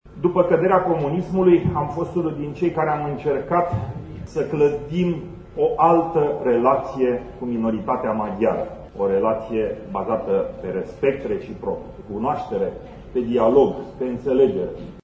De la tribuna congresului, preşedintele Senatului, Călin Popescu-Tăriceanu, a reamintit că împreună cu UDMR a militat pentru drepturile minorităţilor etnice în România: